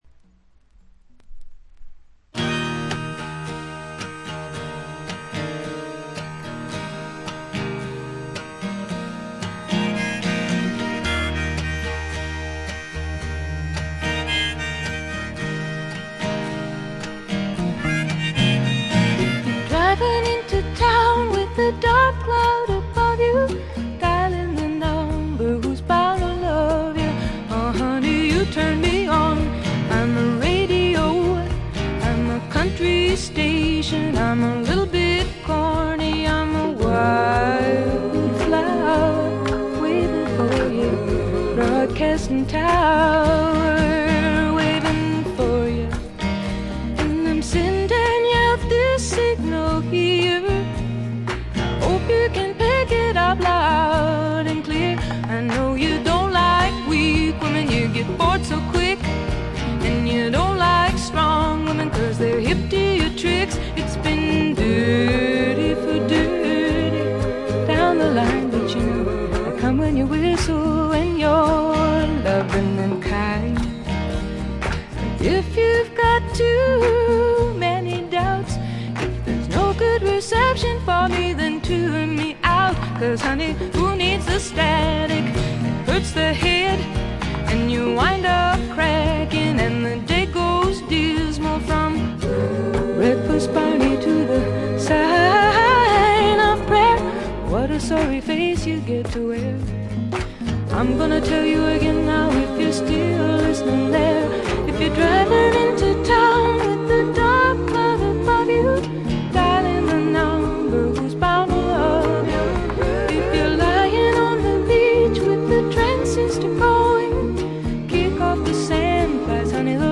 わずかなノイズ感のみ。
試聴曲は現品からの取り込み音源です。
Recorded At - A&M Studios